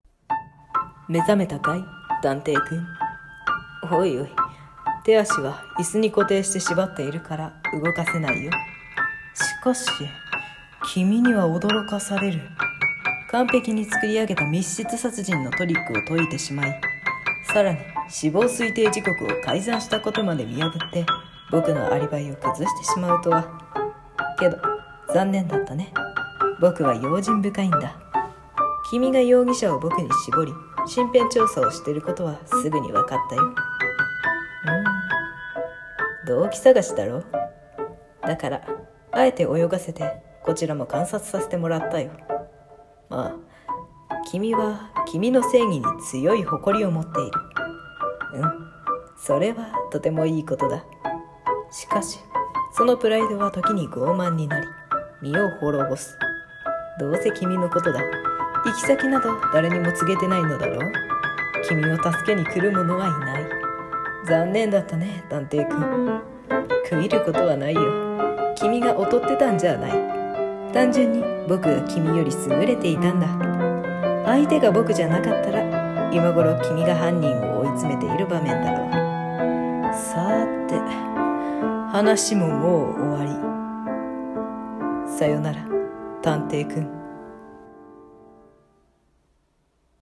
声劇【探偵】※悪役声劇